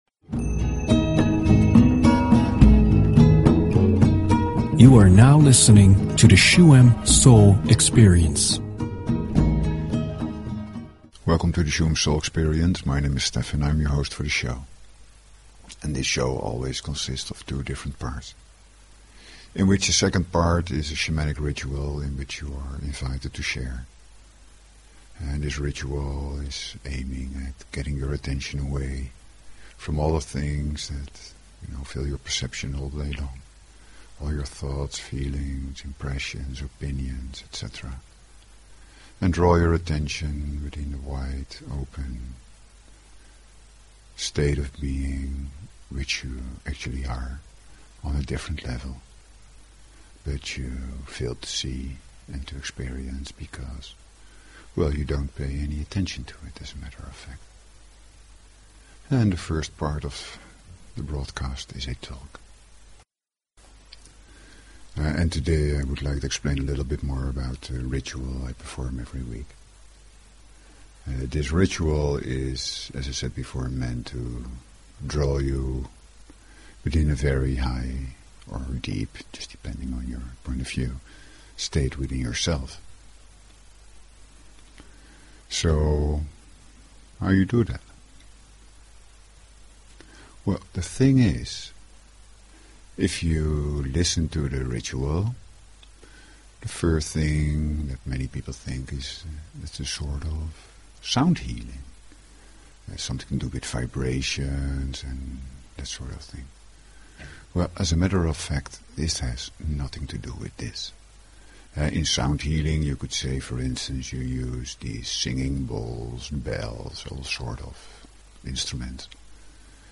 Talk Show Episode, Audio Podcast, Shuem_Soul_Experience and Courtesy of BBS Radio on , show guests , about , categorized as
The second part of the show is a shamanic ritual in which you can directly experience what is talked about.
The sounds of drum, rattle and chant lead your attention away from daily thoughts and feelings to lead it to a more silent space within yourself.